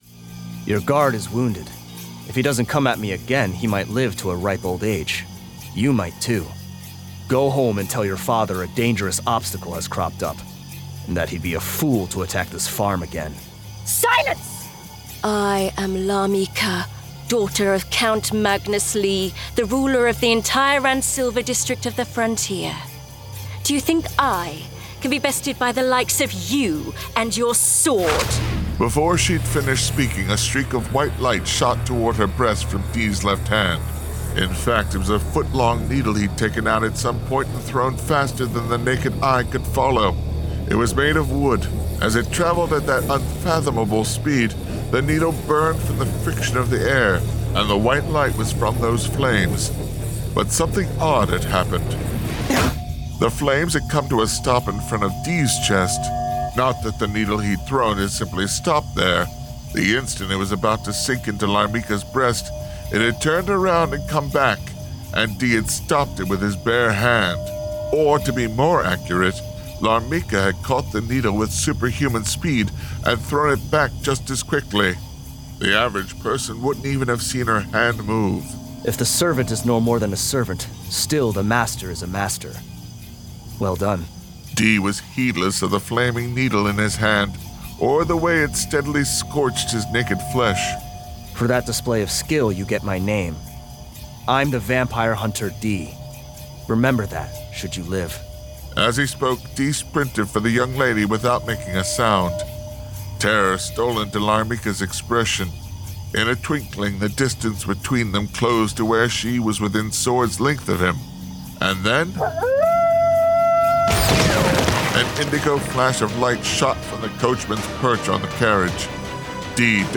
Full Cast. Cinematic Music. Sound Effects.
Genre: Fantasy
Adapted from the novel and produced with a full cast of actors, immersive sound effects and cinematic music!